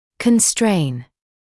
[kən’streɪn][кэн’стрэйн]сдерживать, ограничивать